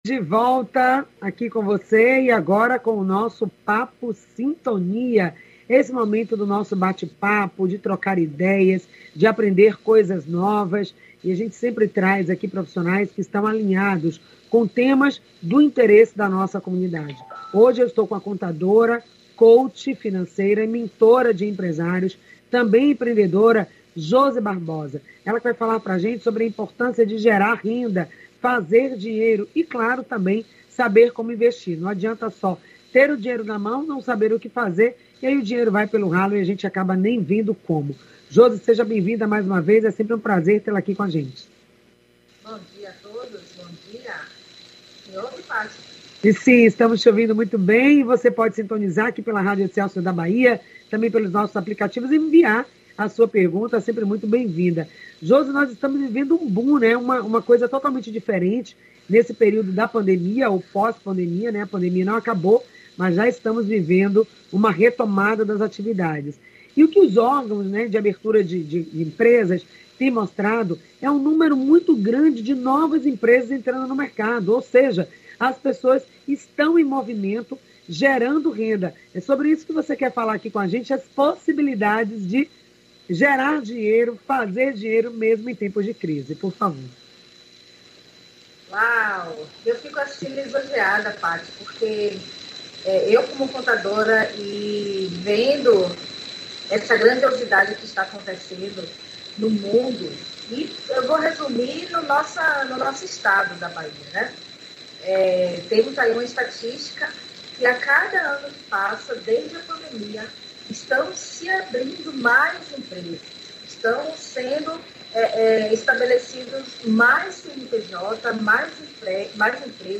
A entrevista